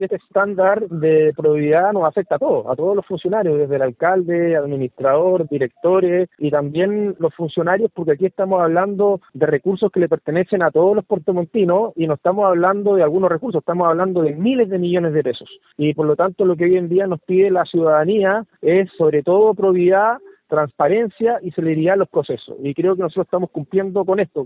En conversación con Radio Bío Bío, el alcalde Rodrigo Wainraihgt, enfatizó que están cumplimiento con lo que pide la ciudadanía, que es “probidad, transparencia y celeridad en los procesos”.